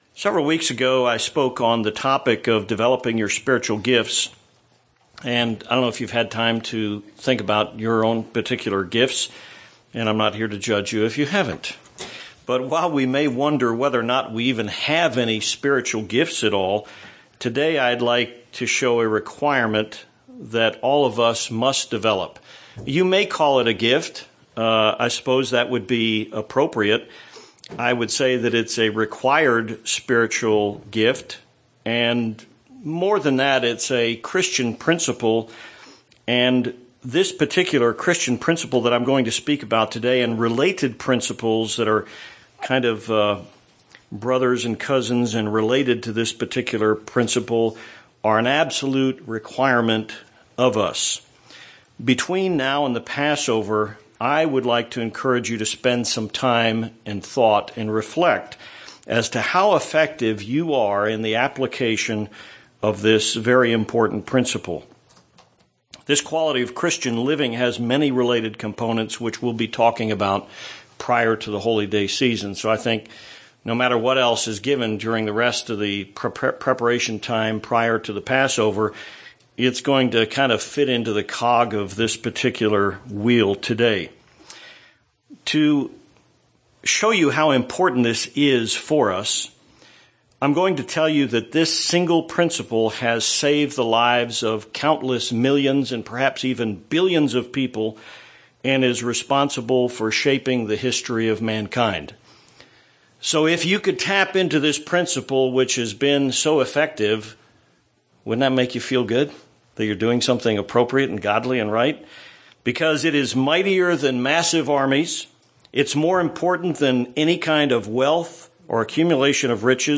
Sermons
Given in Tucson, AZ